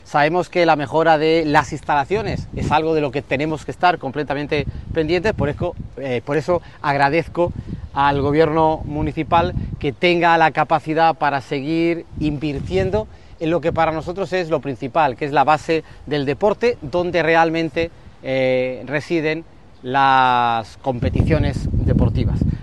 Audio: Declaraciones de la alcaldesa Noelia Arroyo sobre nuevas pistas de tenis (MP3 - 1,30 MB)